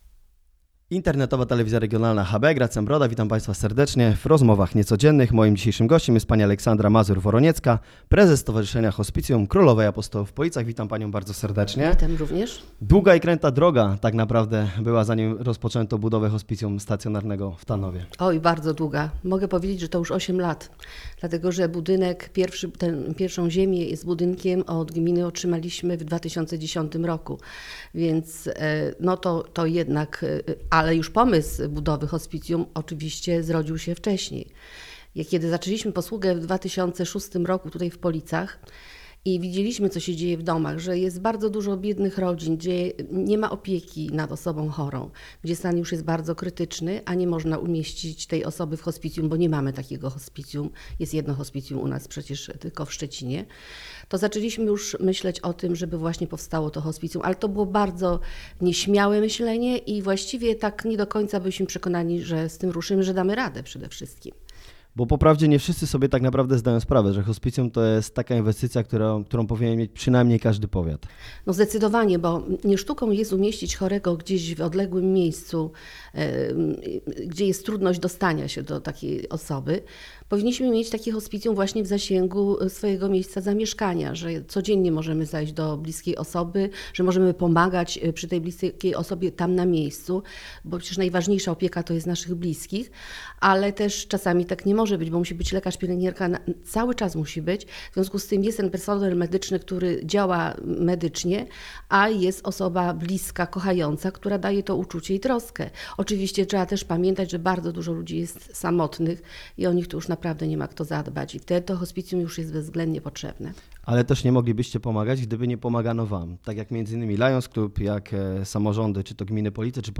Rozmowa Niecodzienna: O hospicjum, wolontariacie i zespole FEEL [wideo/audio]
Dzisiaj na łamach Wirtualnych Polic publikujemy pierwszy odcinek programu publicystycznego "Rozmowa Niecodzienna" poświęconego bieżącym wydarzeniom z życia mieszkańców Polic oraz Powiatu Polickiego.